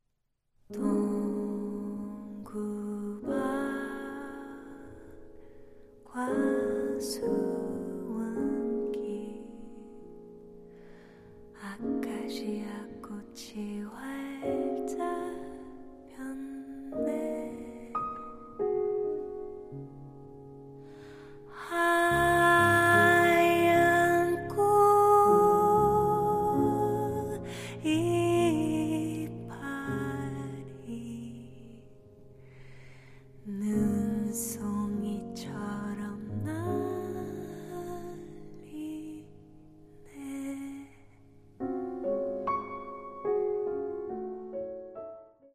voice
piano
accordion